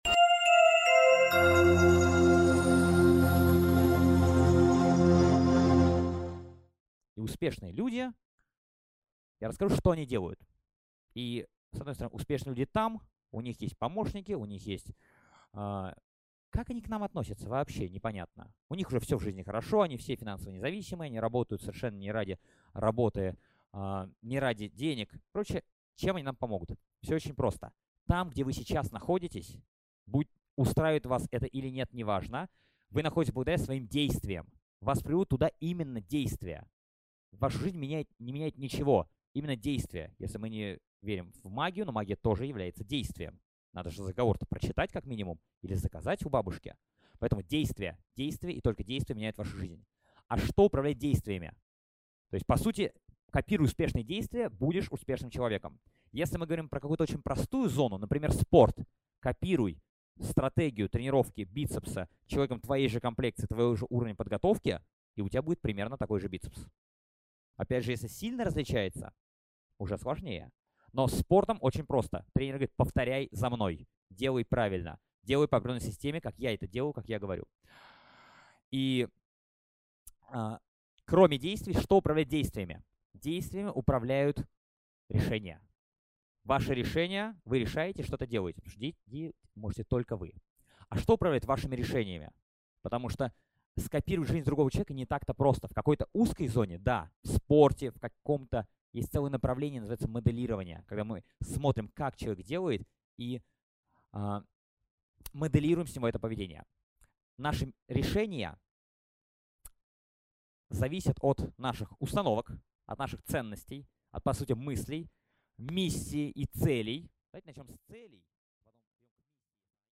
Аудиокнига Привычки успешного человека | Библиотека аудиокниг